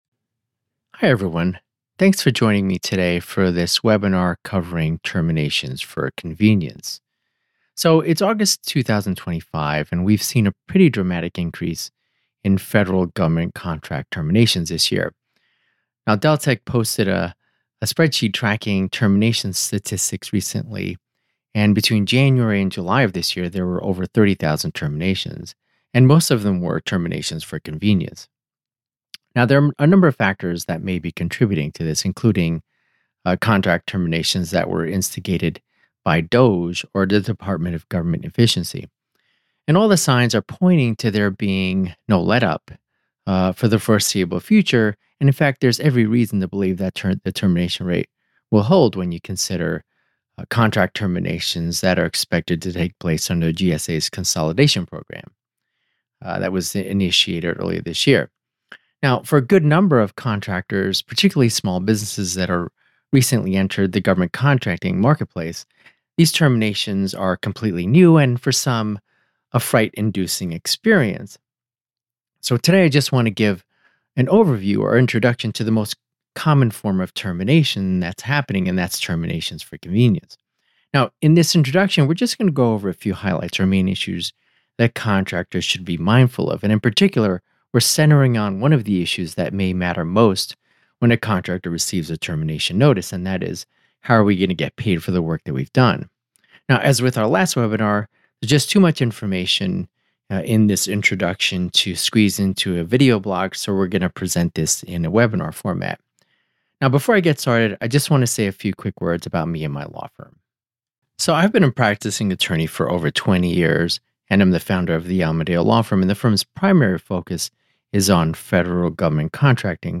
In this webinar